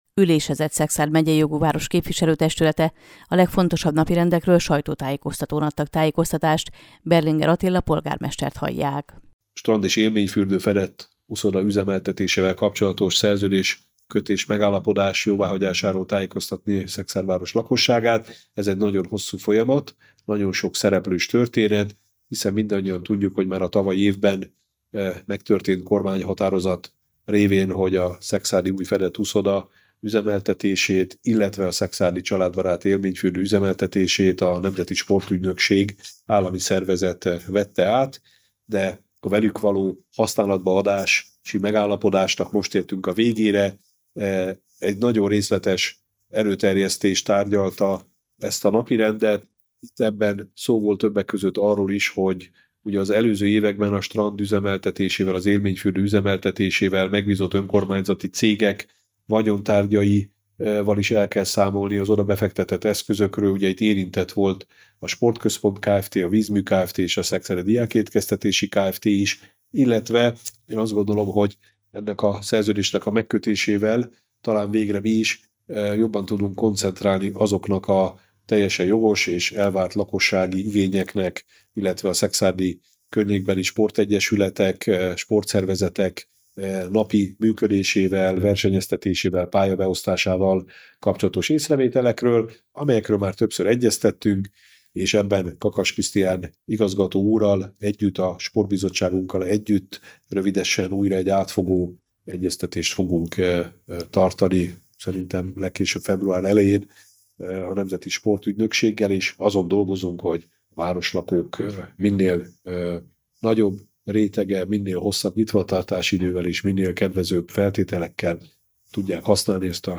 szavalas_teljes_Exp.mp3